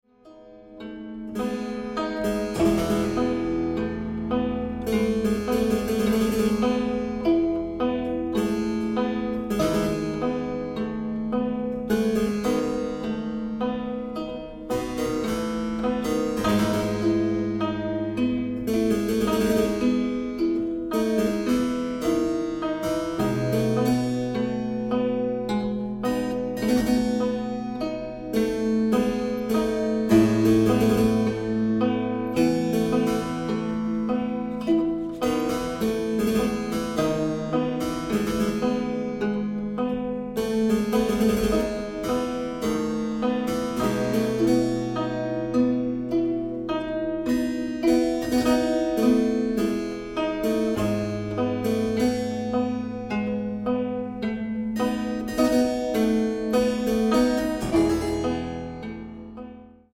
Harpsichord and kacapi indung